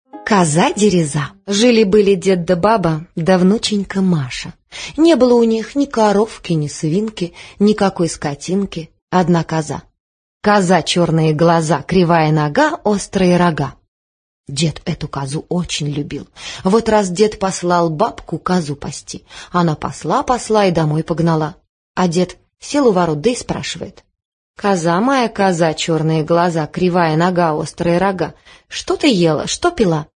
Аудиокнига Коза-Дереза